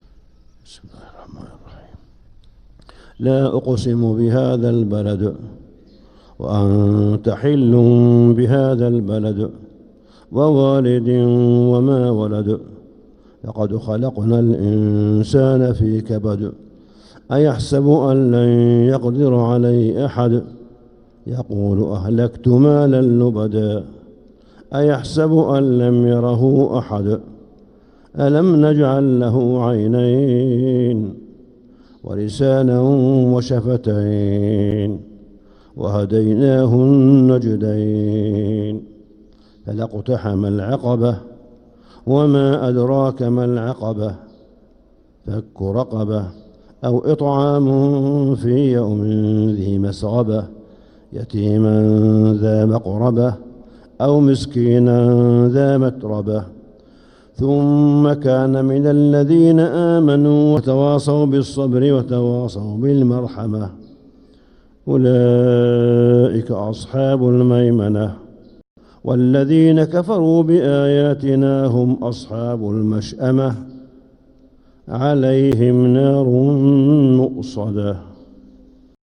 سورة البلد 8-8-1446هـ | Surah Al-Balad > السور المكتملة للشيخ صالح بن حميد من الحرم المكي 🕋 > السور المكتملة 🕋 > المزيد - تلاوات الحرمين